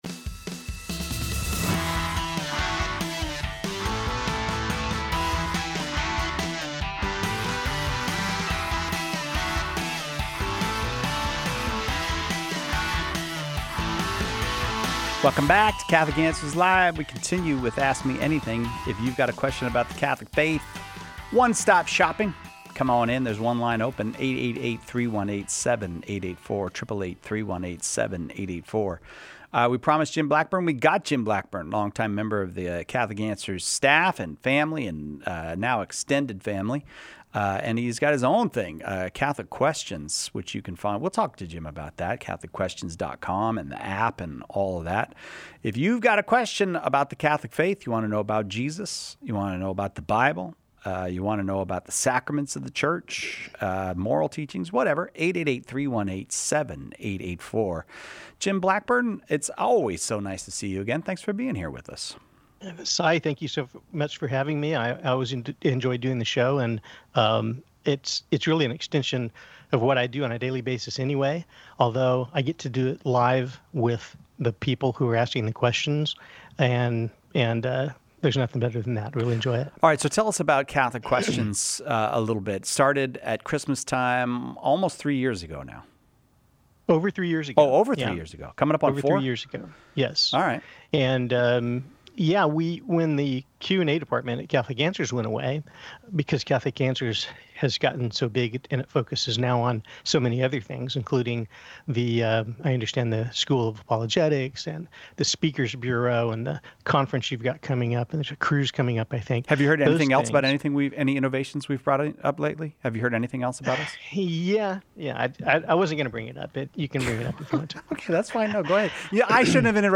In relation to the earlier call on hospice.